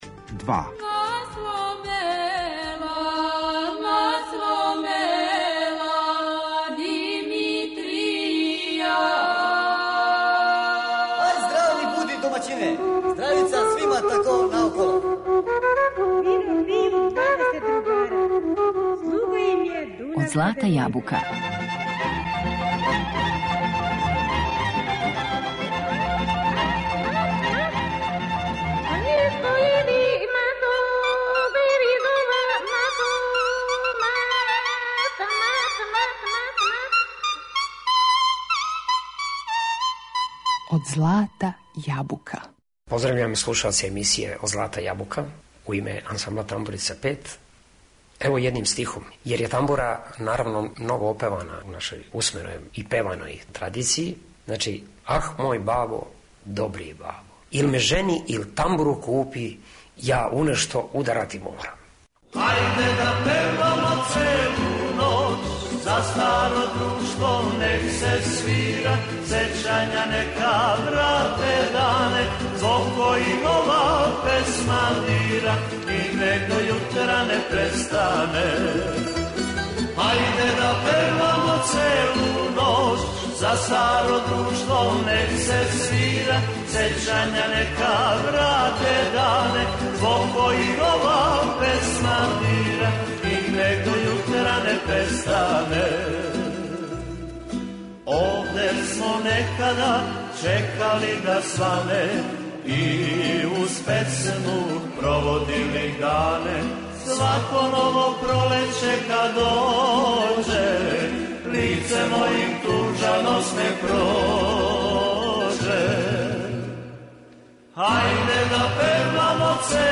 Репертоар овог ансамбла је сачињен од традиционалних народних и градских песама, а поред тога изводе и традиционалнe песмe из Русије, Италије, Грчке, Норвешке, као и популарне комаде, увертире, чардаше ...За дугогодишњи истрајан и плодан рад, ове године добили су значајну награду - Златни беочуг.